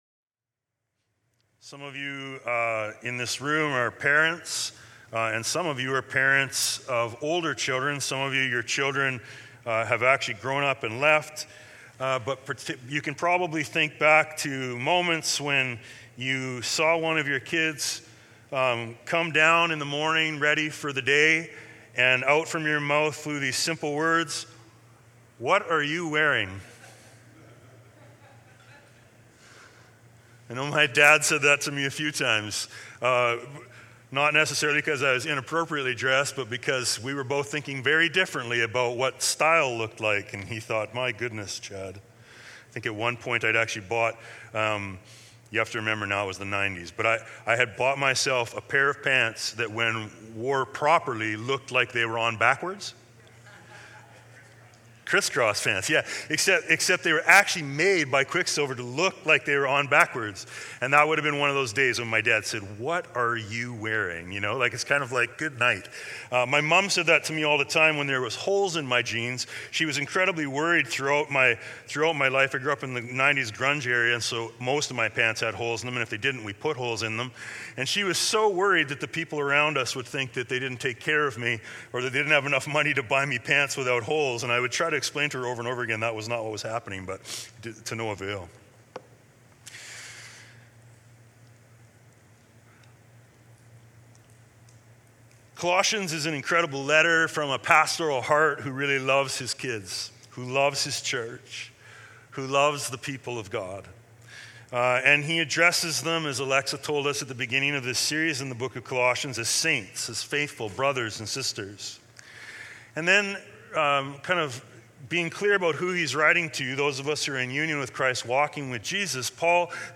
Sermons | Emmaus Road Anglican Church